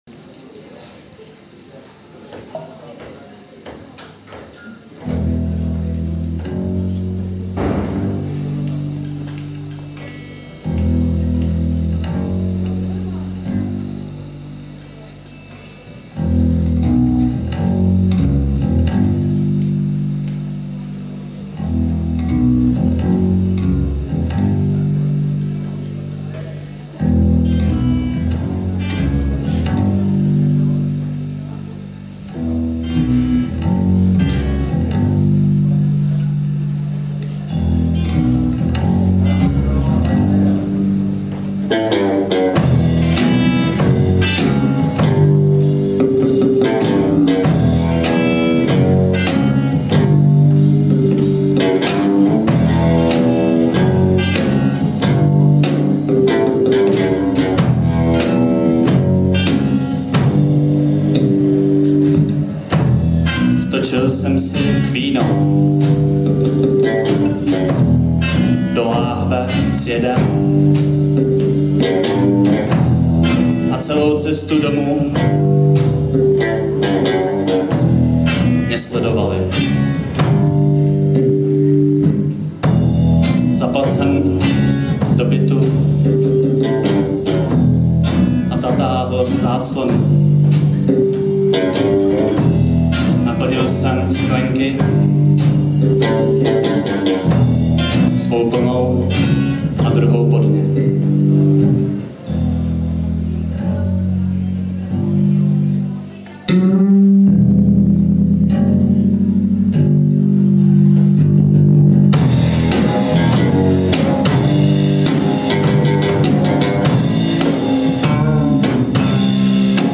První koncert ve třech:
(Jinak z nervozity unisona nejsou unisona big_smile)
jako jste docela mimo rytmus, a že bych si do toho dupal, to se taky říct nedá...
jinak že to občas neladí mi tady přijde spíš jako plus, má to takovou dekadenční atmosféru.